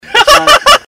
Laugh 24